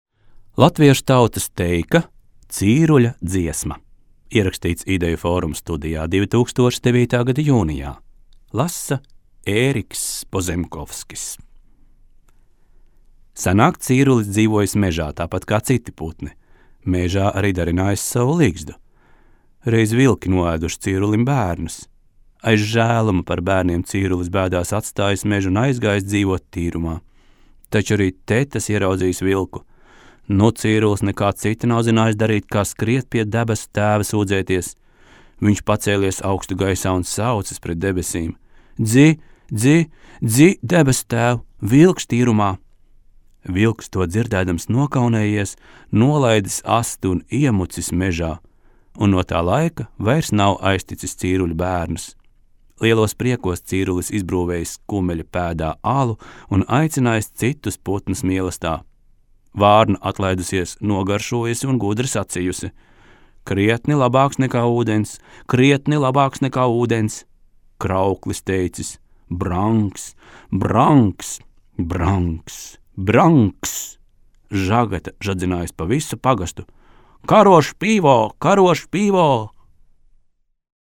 Teikas